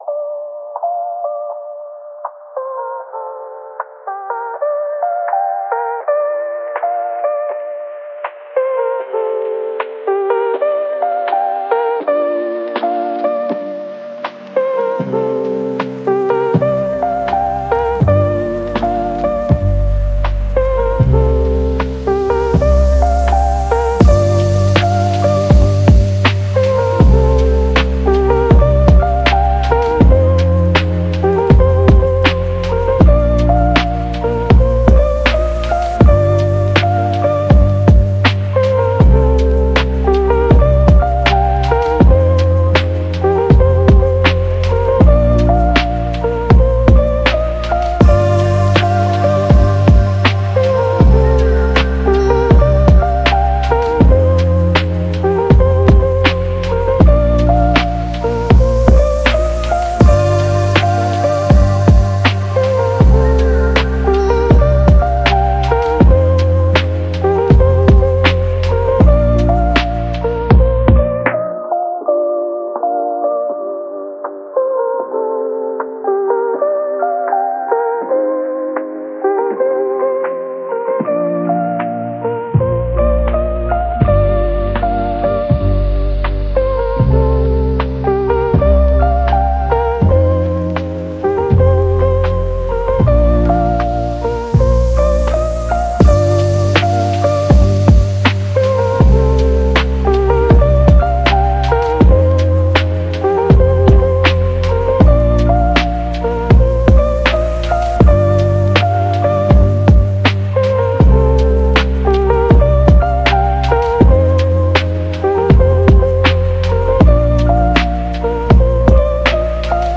Chill Lofi Beat